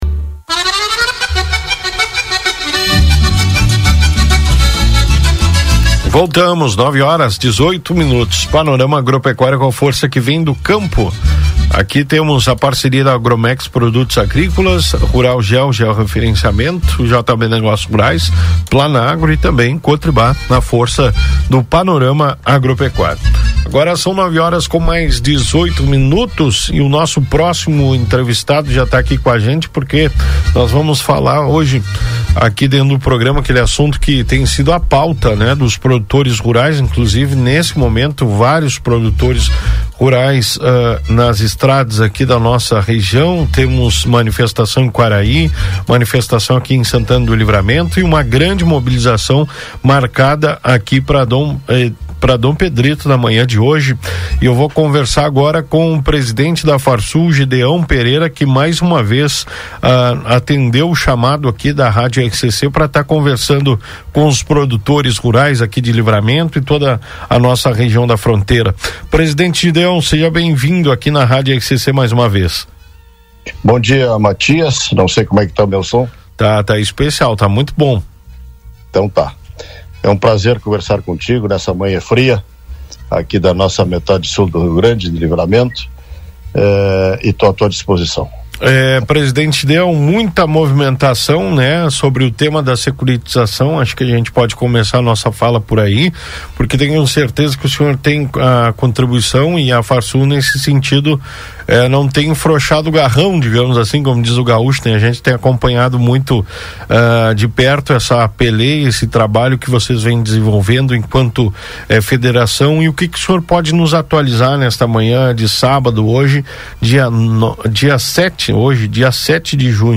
Em entrevista exclusiva à Rádio RCC FM